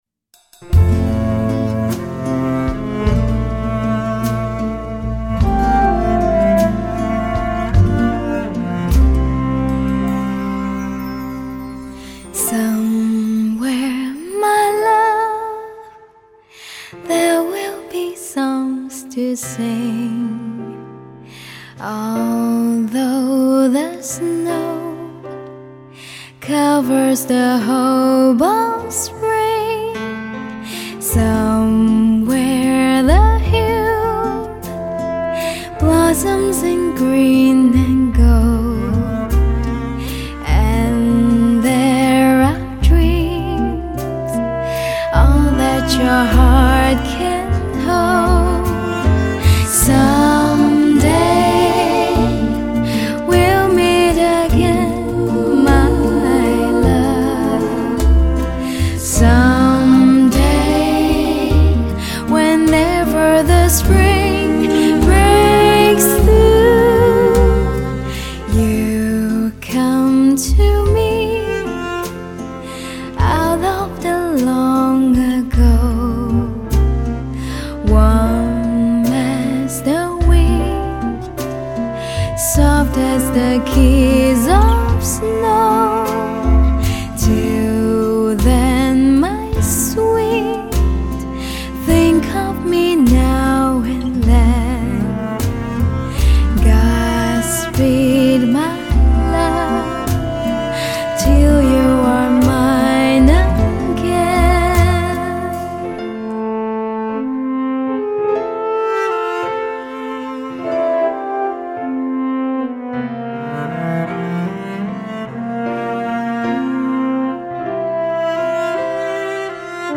来自灵魂深处的声音，浓烈的怀旧气息，浪漫而怡人， 清晰透明的音场感，乐器音乐精细无遗， 人声演绎细腻动人，感人至深。